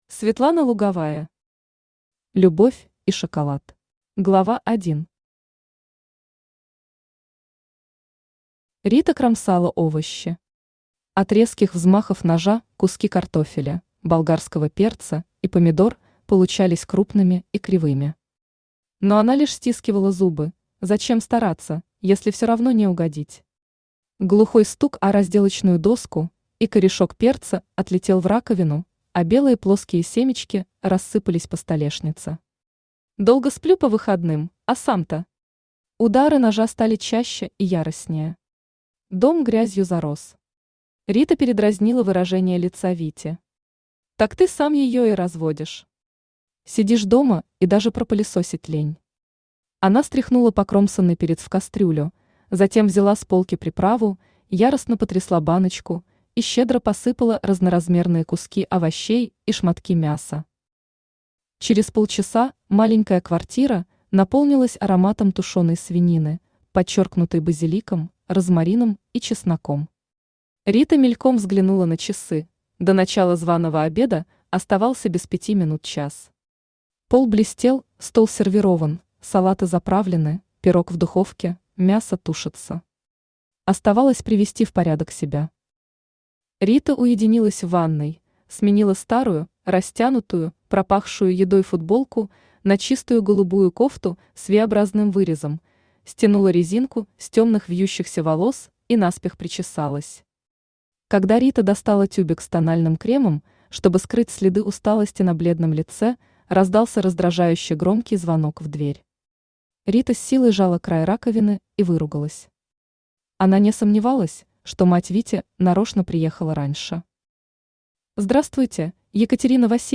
Aудиокнига Любовь и шоколад Автор Светлана Луговая Читает аудиокнигу Авточтец ЛитРес.